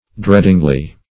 dreadingly - definition of dreadingly - synonyms, pronunciation, spelling from Free Dictionary Search Result for " dreadingly" : The Collaborative International Dictionary of English v.0.48: Dreadingly \Dread"ing*ly\, adv.